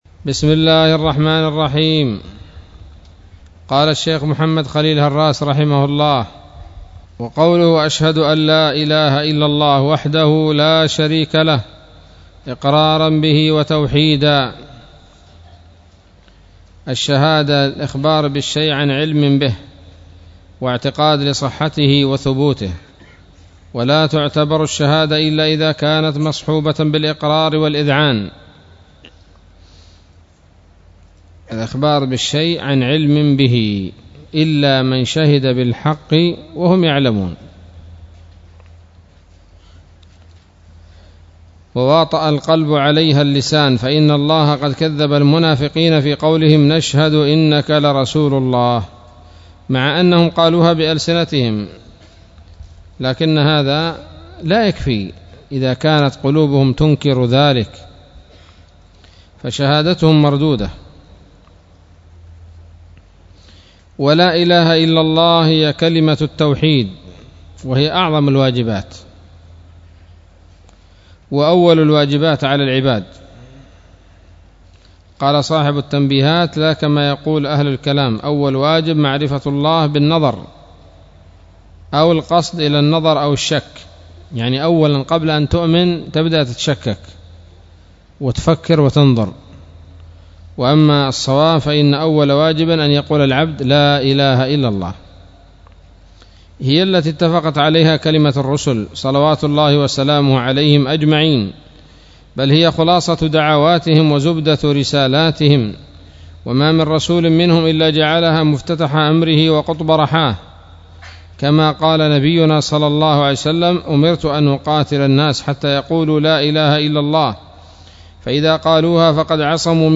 الدرس الثالث عشر من شرح العقيدة الواسطية للهراس